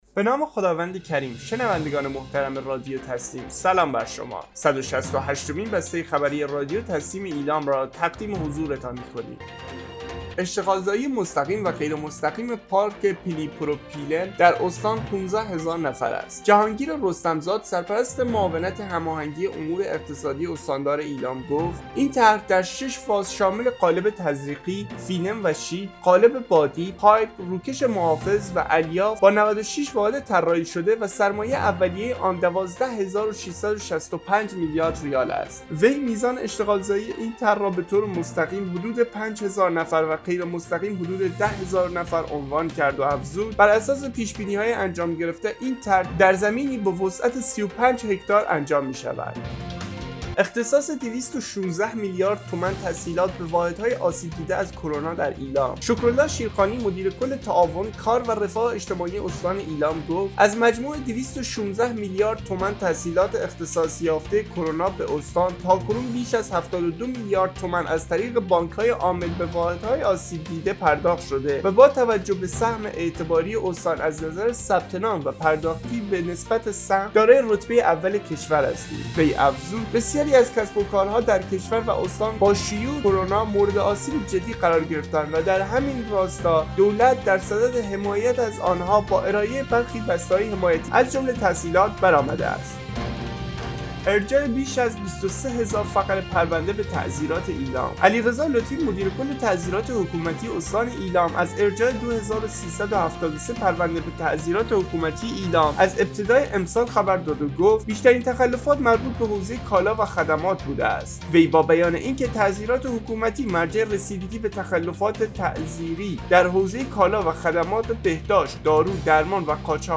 به گزارش خبرگزاری تسنیم از ایلام، صد و شصت و هشتمین بسته خبری رادیو تسنیم استان ایلام باخبرهایی چون اختصاص 216 میلیارد تومان تسهیلات به واحدهای آسیب‌دیده از کرونا در ایلام، ارجاع بیش از 23 هزار فقره پرونده به تعزیرات ایلام و اشتغال‌زایی مستقیم و غیرمستقیم پارک پلی‌پروپیلن در استان 15 هزار نفر است، منتشر شد.